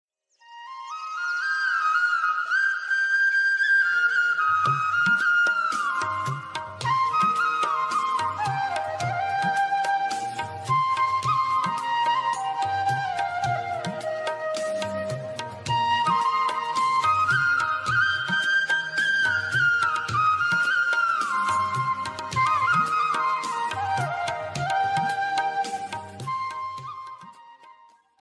Enjoy the classic romantic melody as your mobile ringtone.